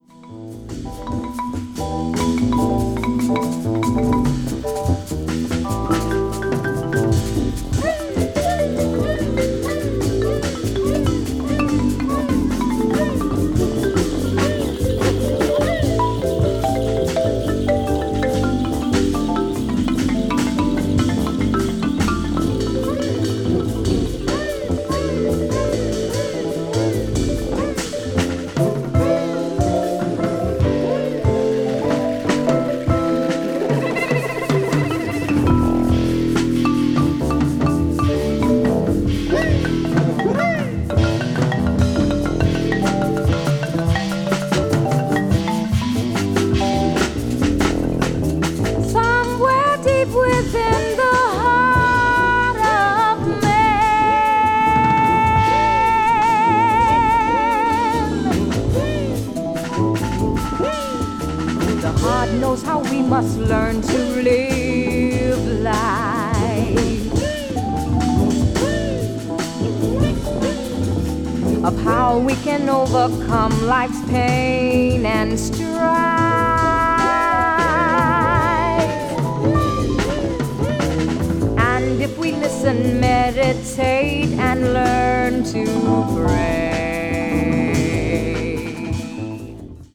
media : EX-/EX(わずかにチリノイズが入る箇所あり,A:再生音に影響ない薄いスリキズ2本あり)